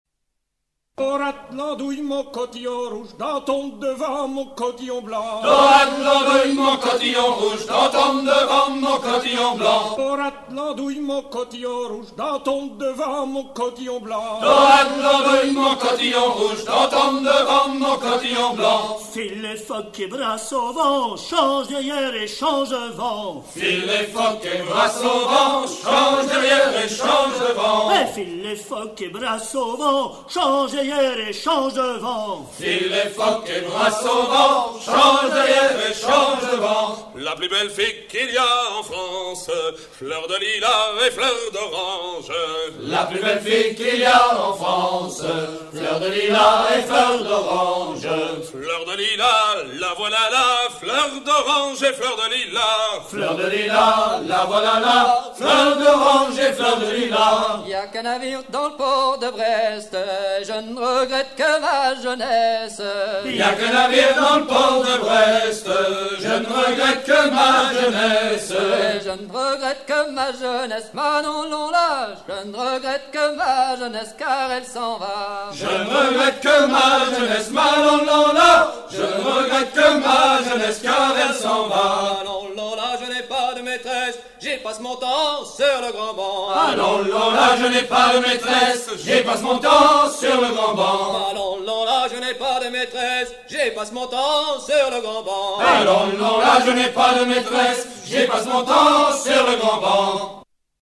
Suite de chants à hisser main sur main
refrains utilisés pour hisser les voiles sur les terre_neuvas